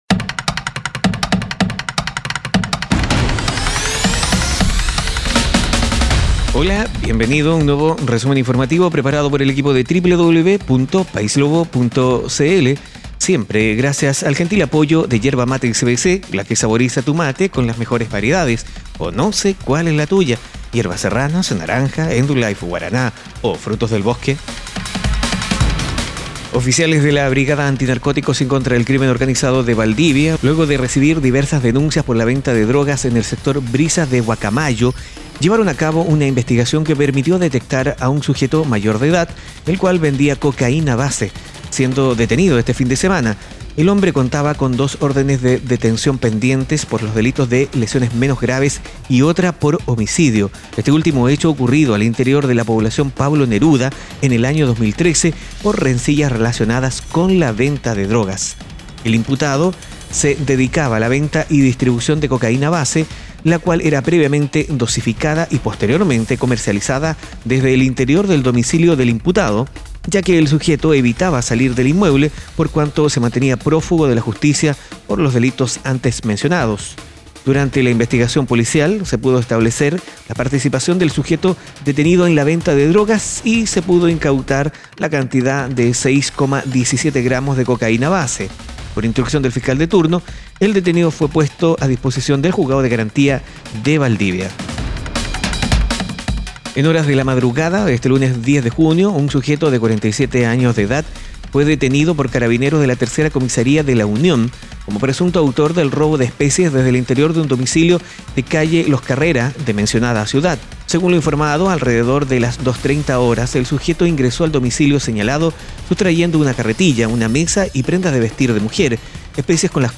Noticias e informaciones en pocos minutos.